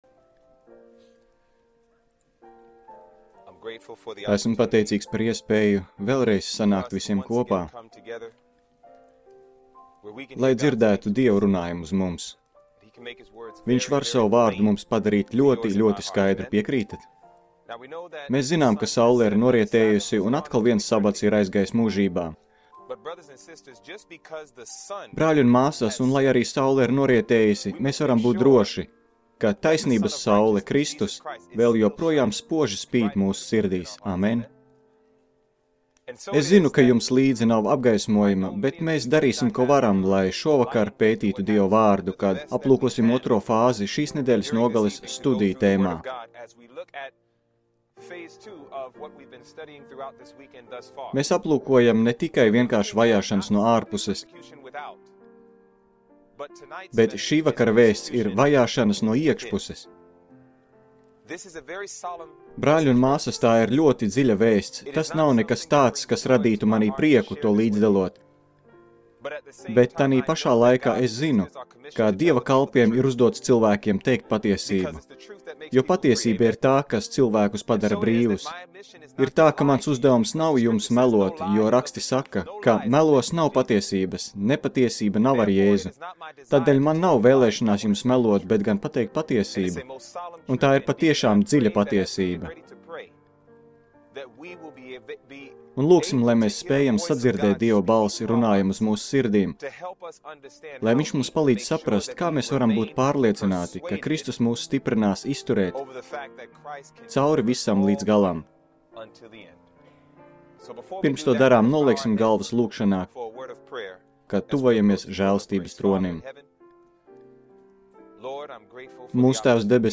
Seminārs